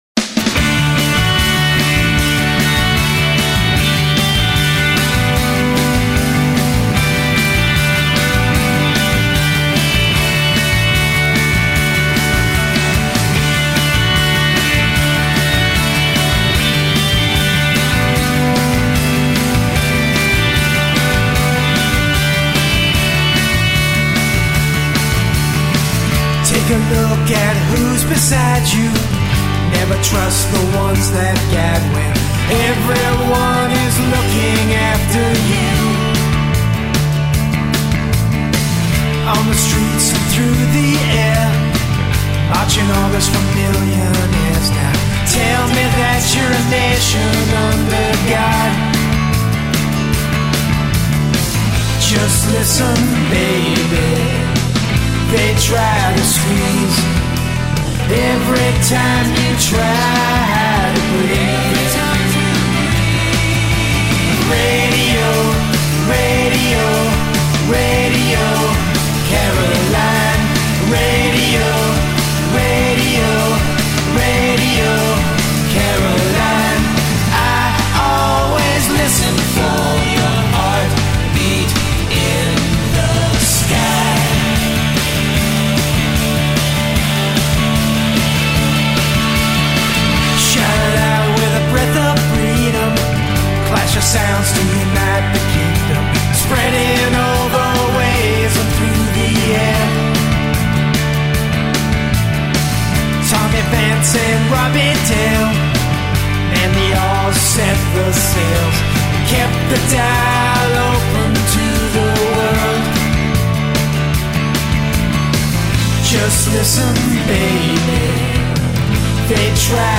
Rock polished to quite a shine.